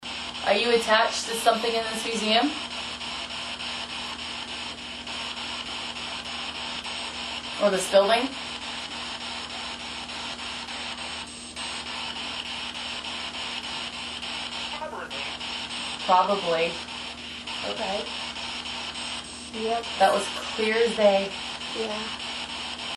"Probably" - spirit box
During a session with the spirit box, a voice answers "probably" to one of our questions. The spirit box had been relatively quiet before and after this.